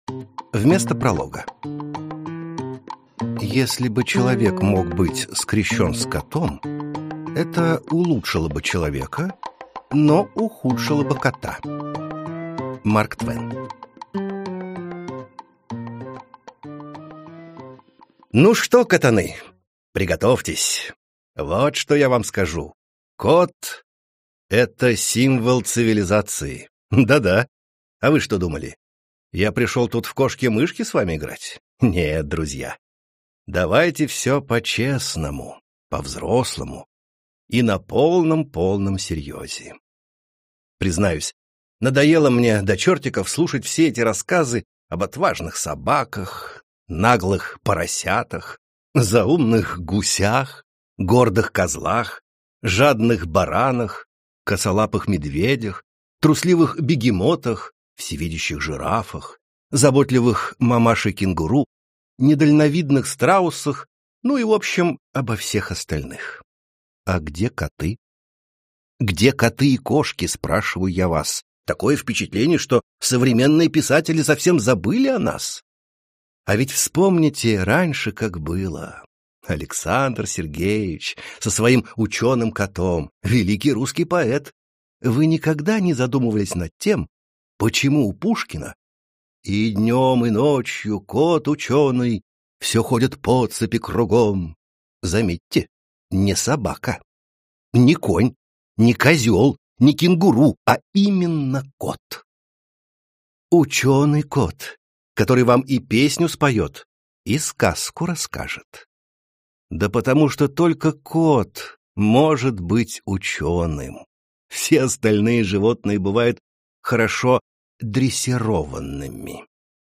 Аудиокнига Невероятные приключения кота Сократа | Библиотека аудиокниг